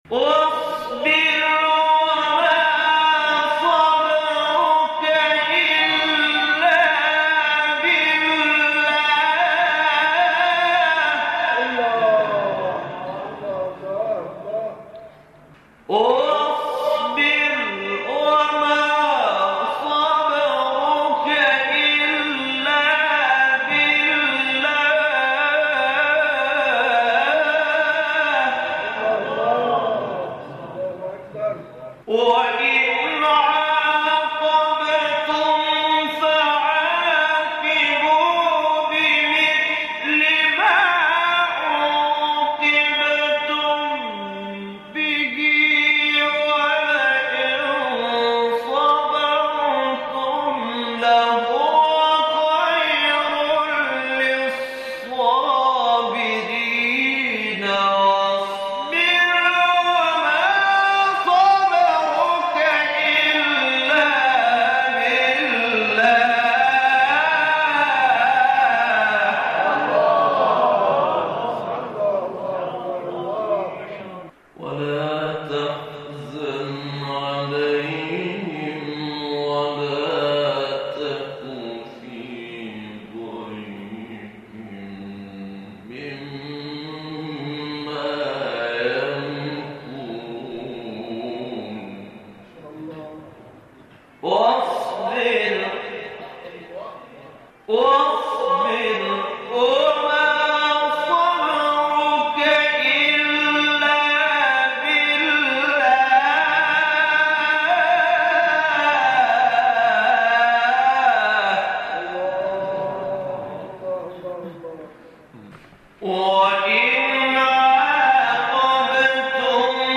گروه شبکه اجتماعی: نغمات صوتی از تلاوت قاریان ممتاز و بین‌المللی کشور را می‌شنوید.